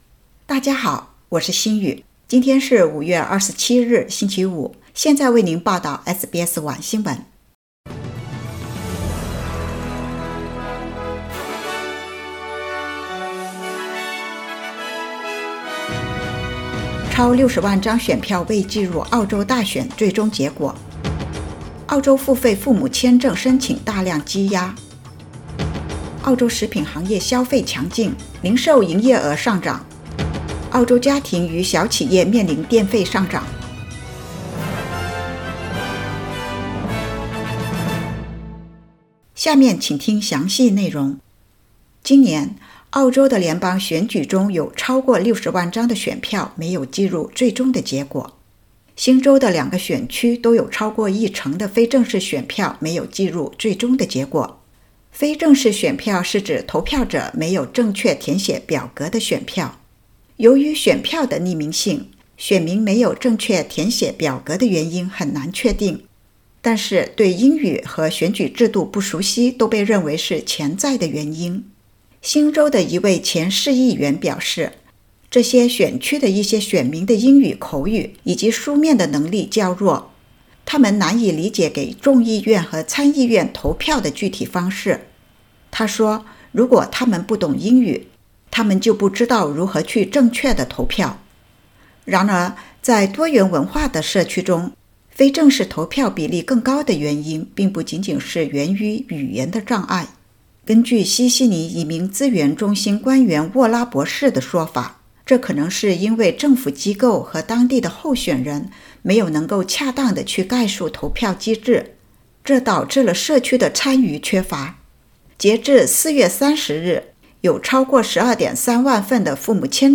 SBS晚新聞（2022年5月27日）
SBS Mandarin evening news Source: Getty Images